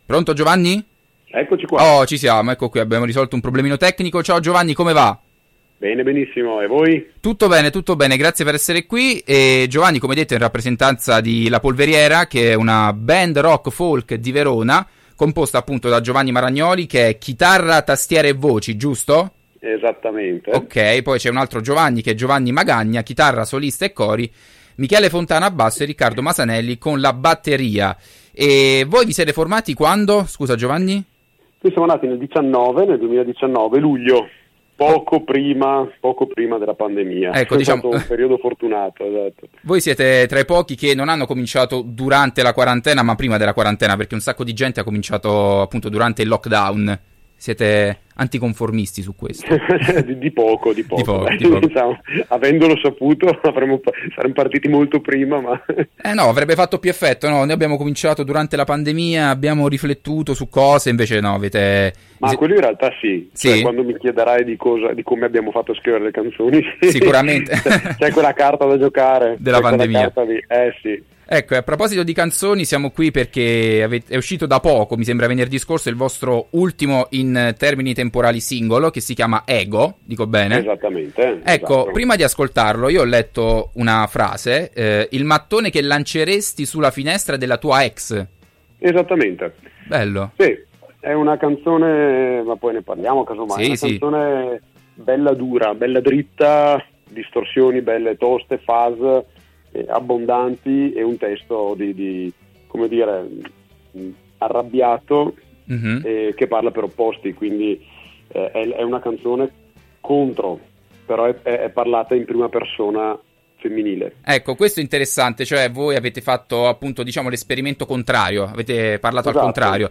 Intervista-la-polveriera.mp3